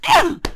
cudgel_attack3.wav